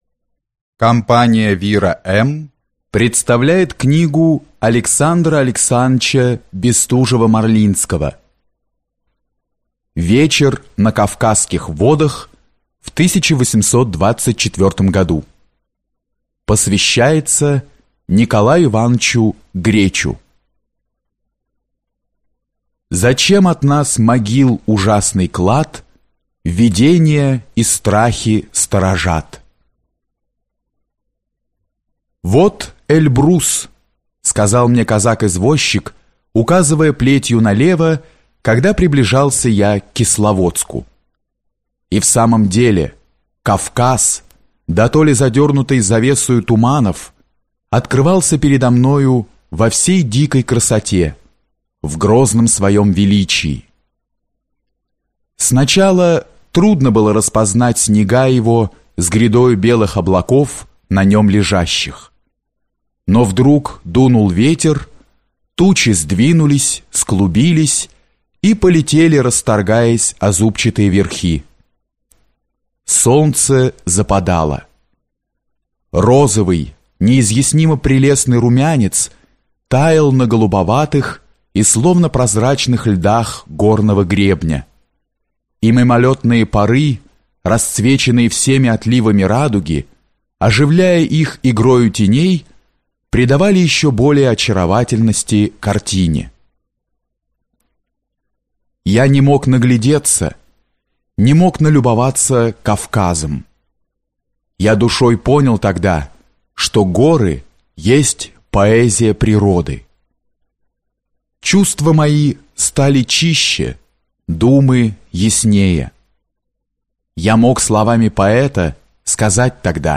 Аудиокнига
Жанр: Повести